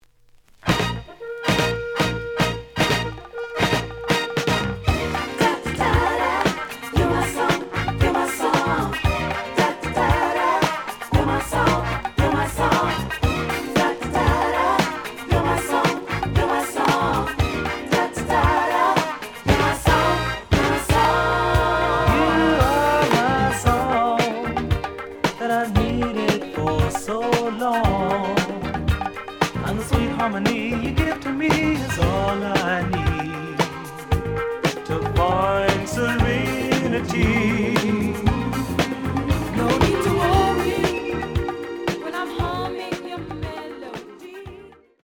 試聴は実際のレコードから録音しています。
The audio sample is recorded from the actual item.
●Genre: Disco